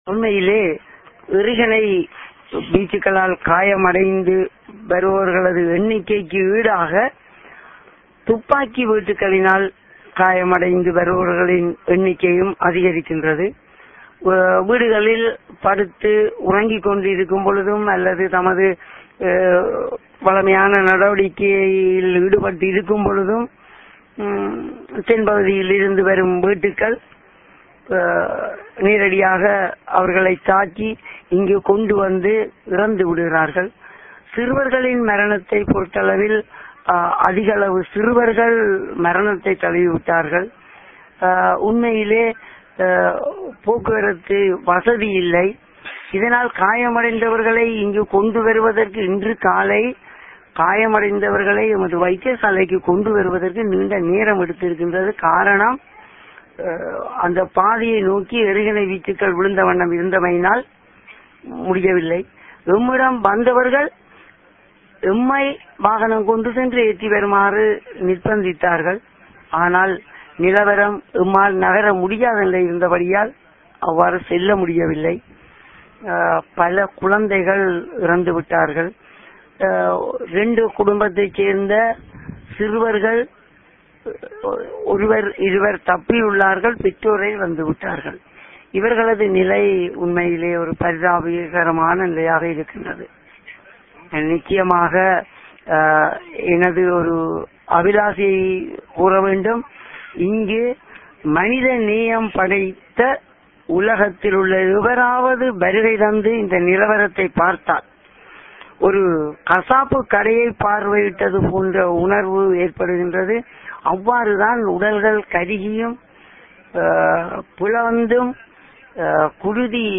Voice: Medical staff talking to TamilNet